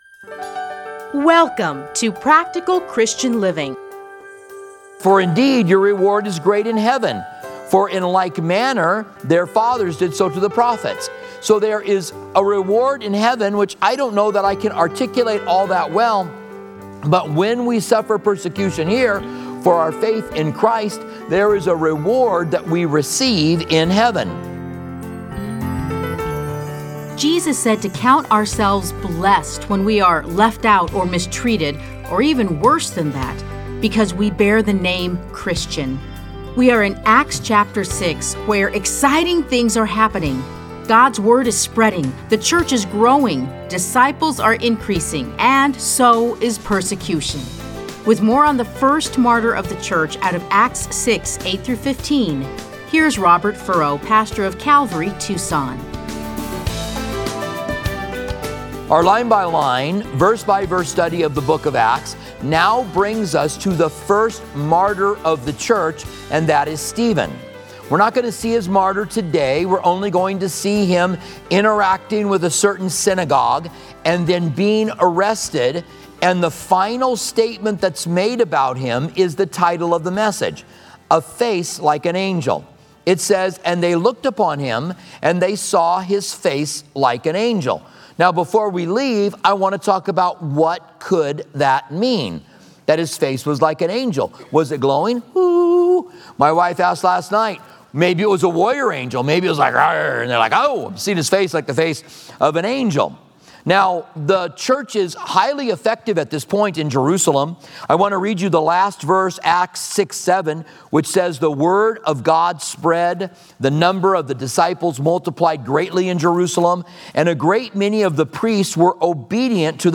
Listen to a teaching from Acts 6:8-15.